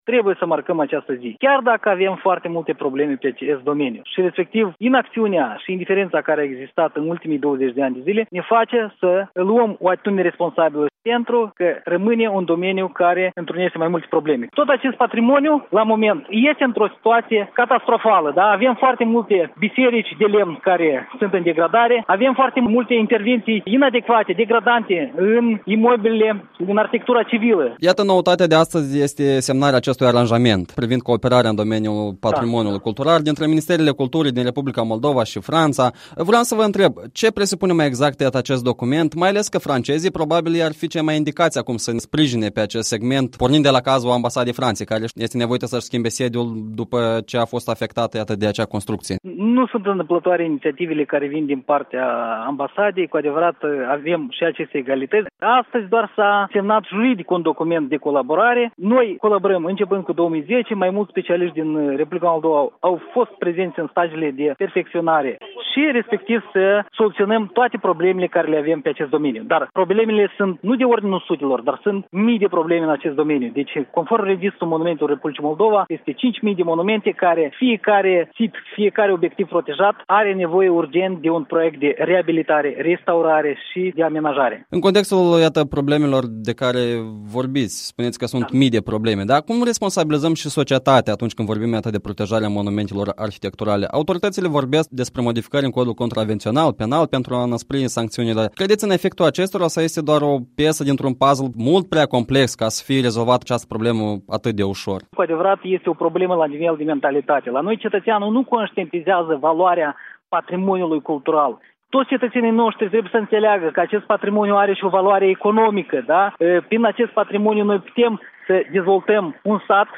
Interviu cu directorul director general al Agenției de inspectare și restaurare a monumentelor din R.Moldova.